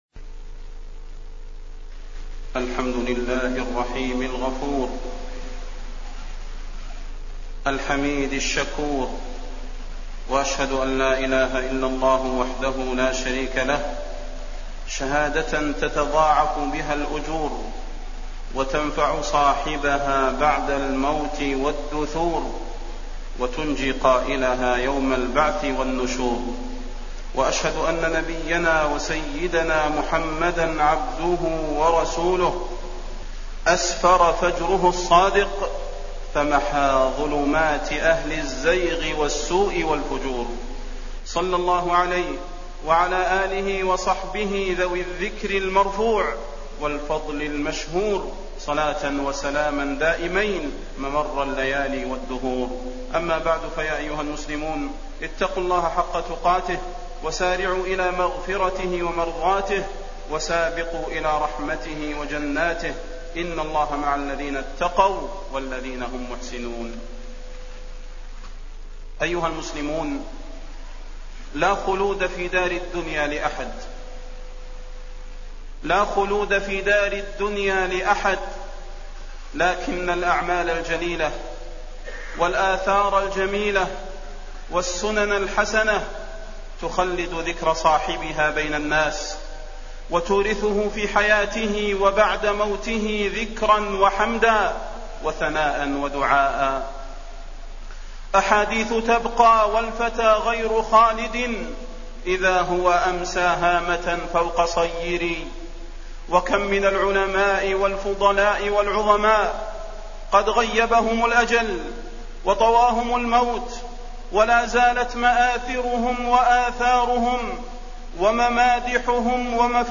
تاريخ النشر ٢٦ جمادى الآخرة ١٤٣٠ هـ المكان: المسجد النبوي الشيخ: فضيلة الشيخ د. صلاح بن محمد البدير فضيلة الشيخ د. صلاح بن محمد البدير بقاء الذكر الحسن للعبد